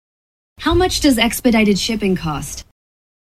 女性の発言に対する返答として最もふさわしいのは以下のどれでしょうか。